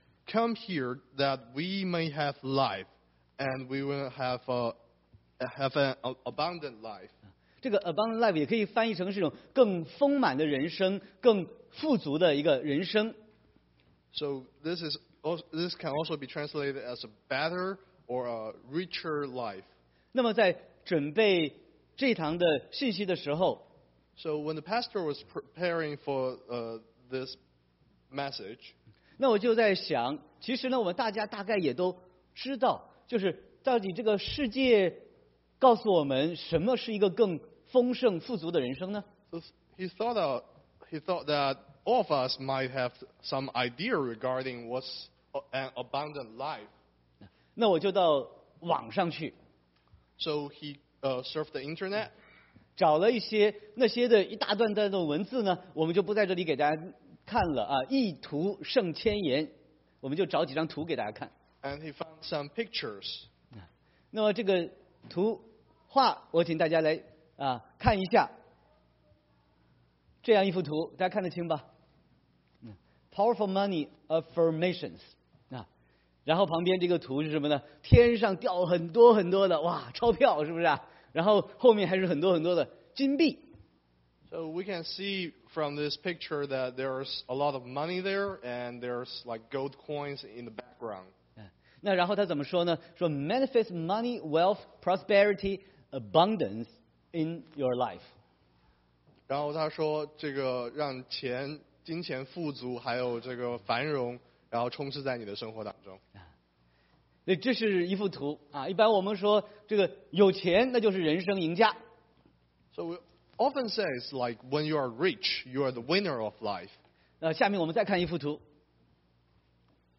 Sermon 4/1/2018